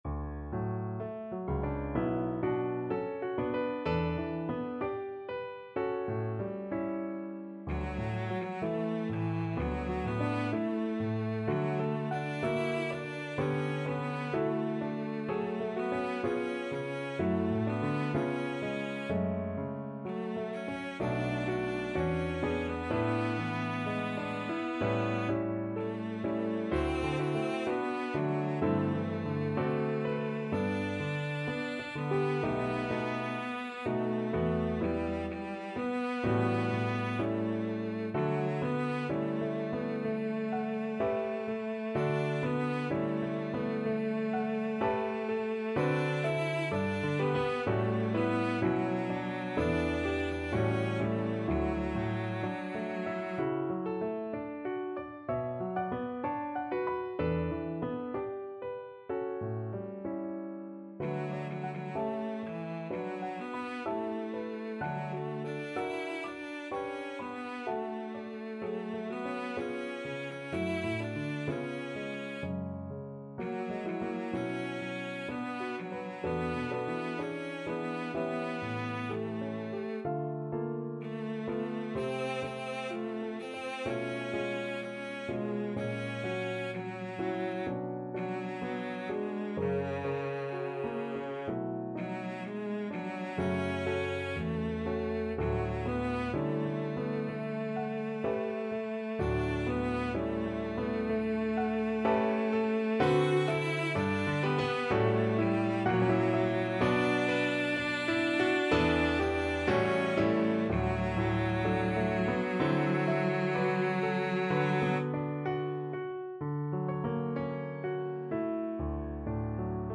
Cello
D major (Sounding Pitch) (View more D major Music for Cello )
4/4 (View more 4/4 Music)
Andante moderato poco con moto =63) (View more music marked Andante Moderato)
Classical (View more Classical Cello Music)